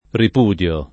rip2dLo], ‑di (raro, alla lat., -dii) — meno com. repudiare: repudio [